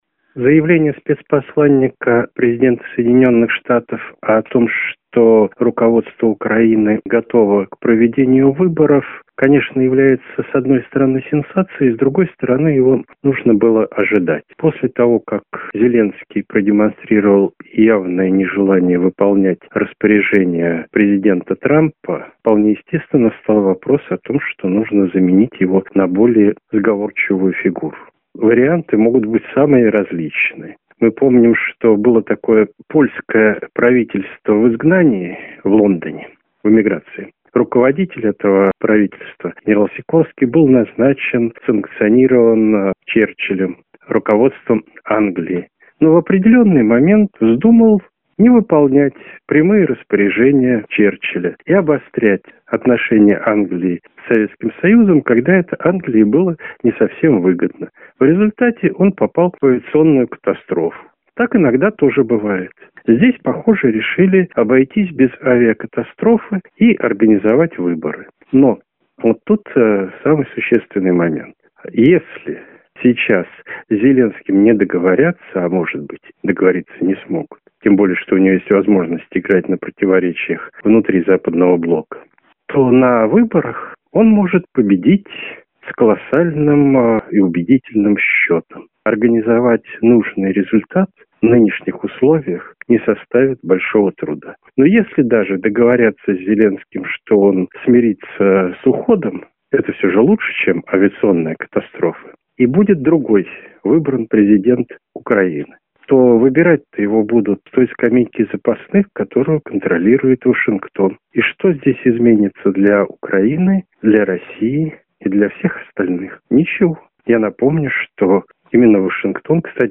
историк и политолог